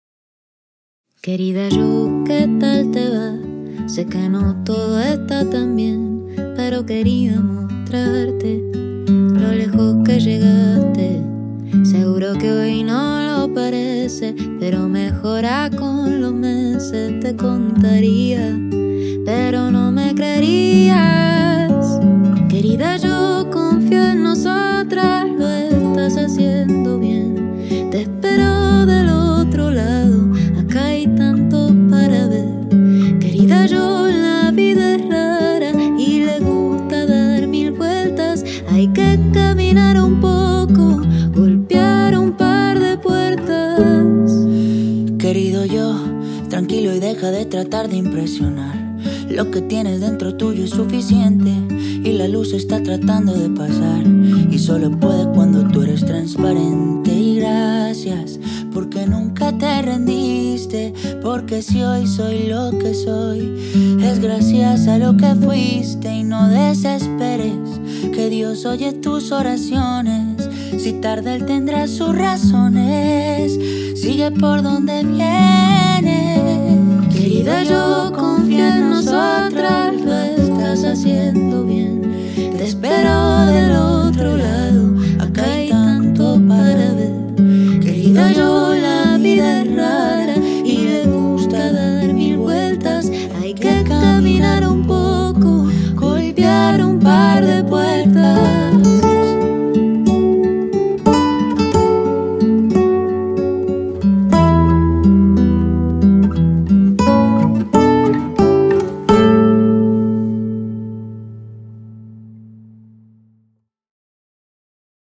un tema íntimo y conmovedor que invita a la reflexión